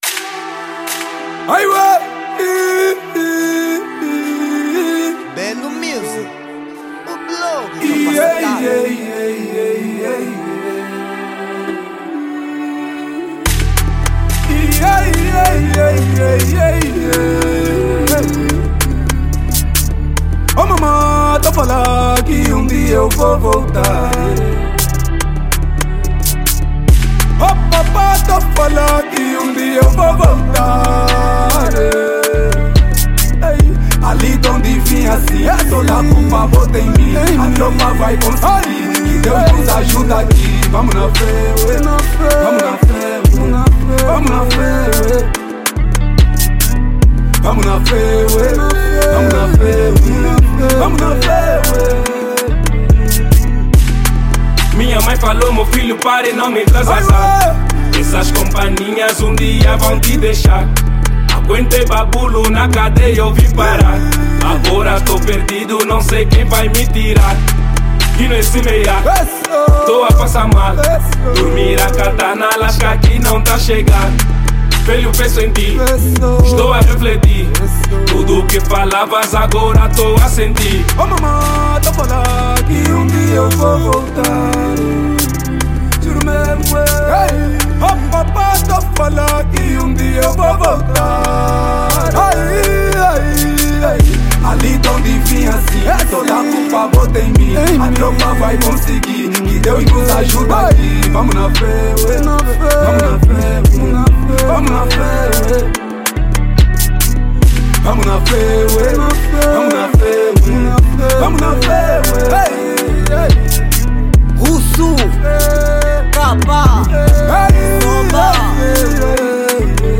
Género: Kuduro